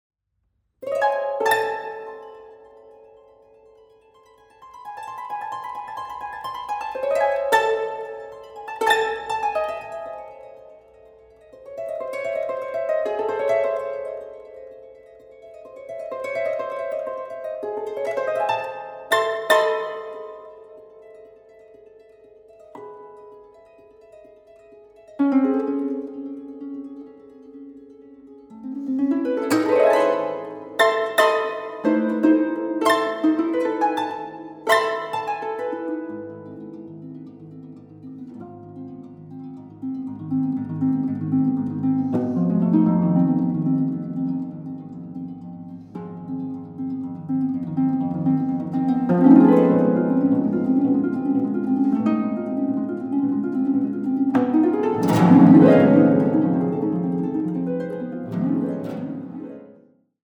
Contemporary Music for Harp
Harp